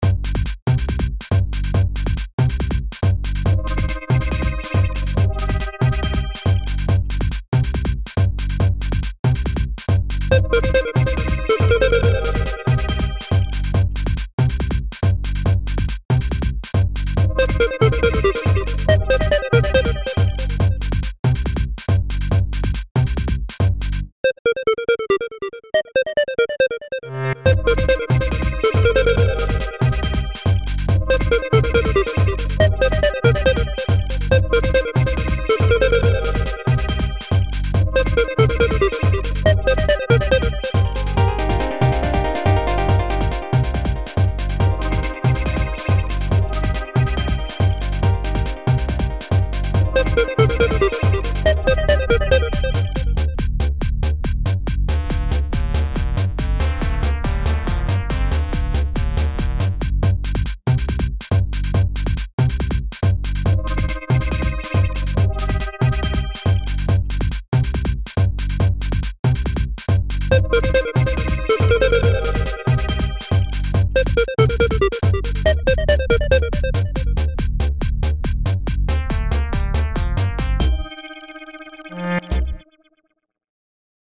Genre(s): Beats, Electronic, Instrumental
Instrumentation: Drums, Synth